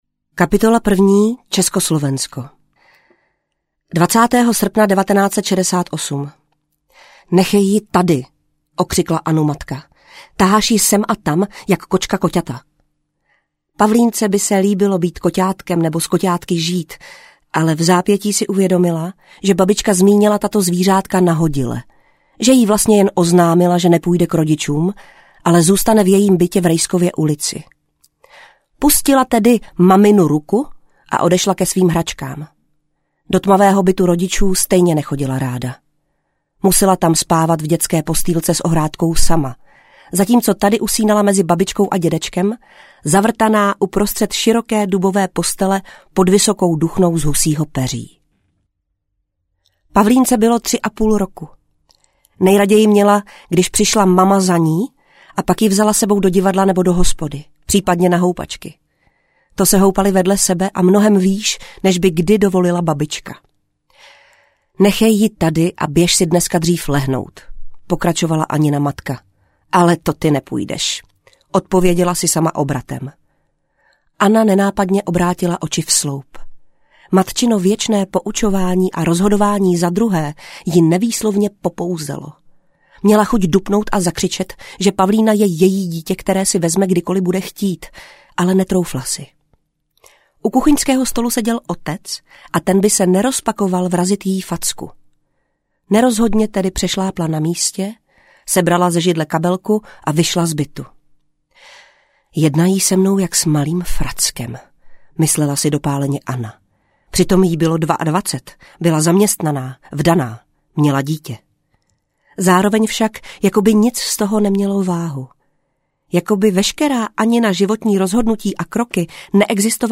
Případ Pavlína audiokniha
Ukázka z knihy
• InterpretPetra Špalková